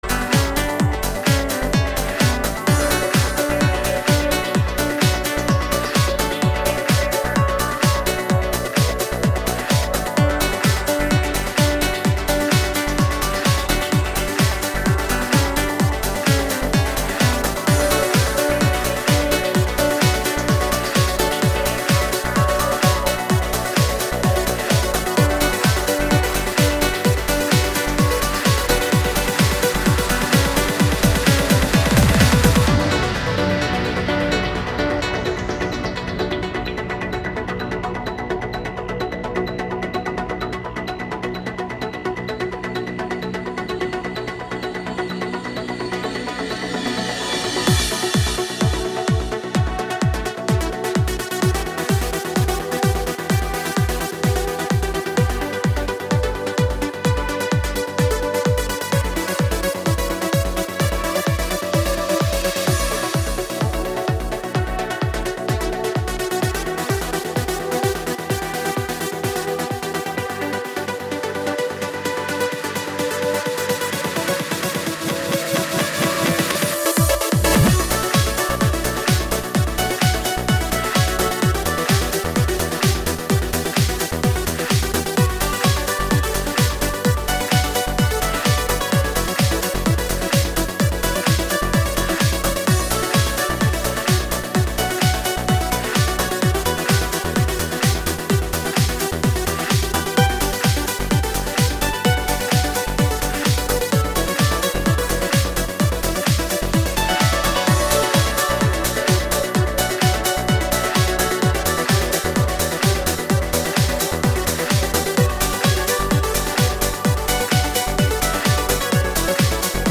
Категория: Club - Mix